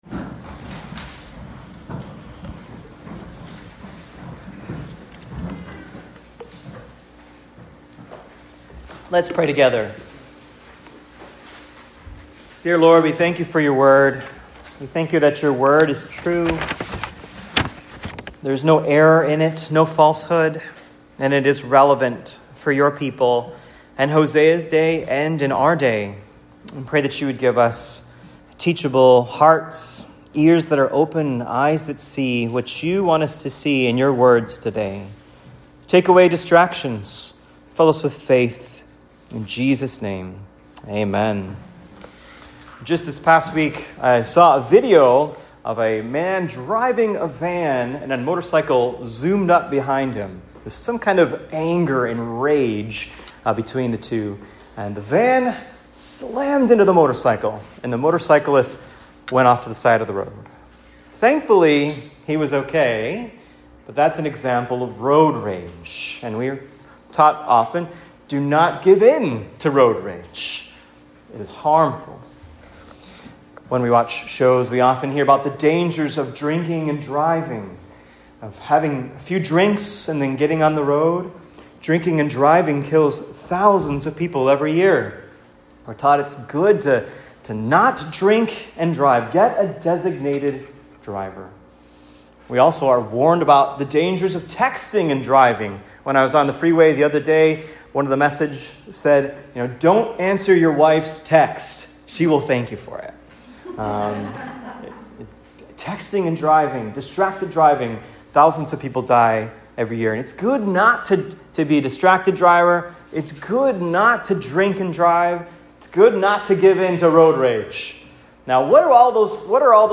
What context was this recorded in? Here's a sermon on Hosea 6-7 on "Repentance" preached at Cross of Christ Fellowship in Naperville on 5.18.25.